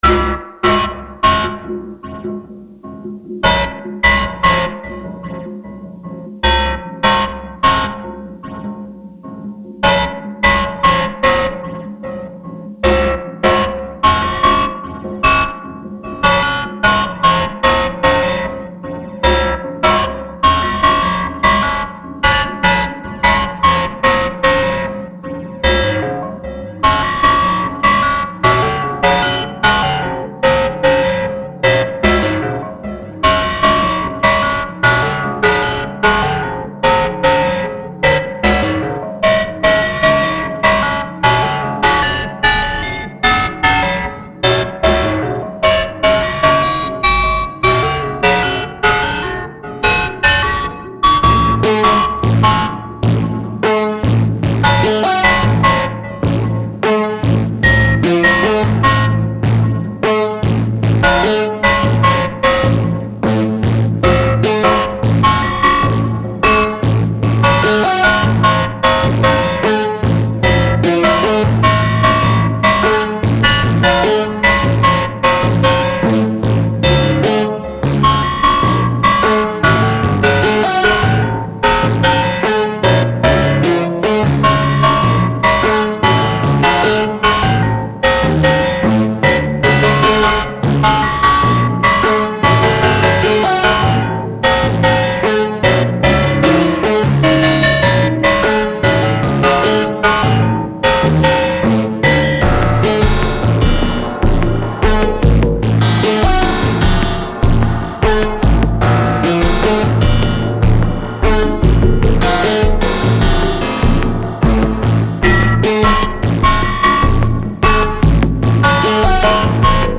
With some overdrive and resonant filtering, it gets pretty clangorous.
Voice 3: Clangs from Arcsyn using three FM voices, through Ratshack Reverb and ET-301.
Kick drum: 0-Coast, with its square wave running through Three Sisters modulated by a Kermit channel. DubStation VST.
Claps: Noise from Disting's S+H mode, through Sputnik VCA and Synchrodyne 2-pole lowpass.
Hi-hats: Waverazor through Valhalla Plate.